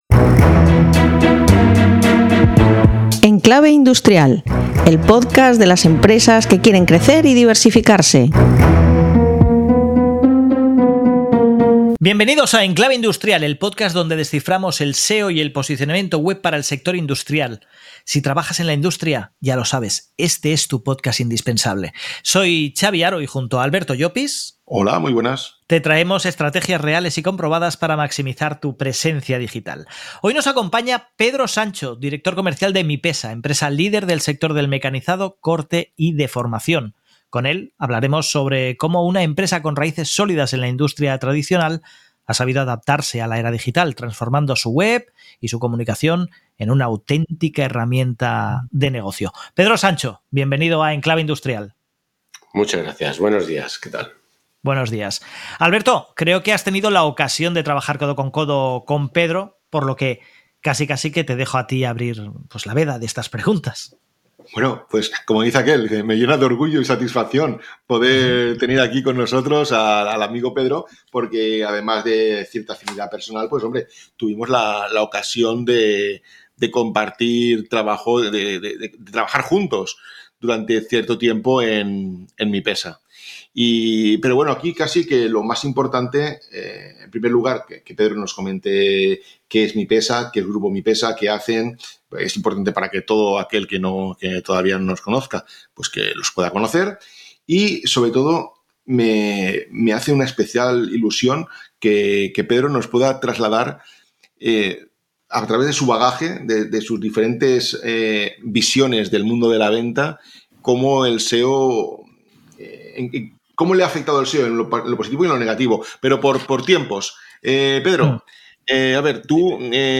Una conversación inspiradora para todos aquellos que quieren llegar a los clientes con el cierre más próximo y con una relación mucho más estrecha.